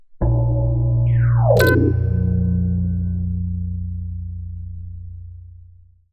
UI_SFX_Pack_61_35.wav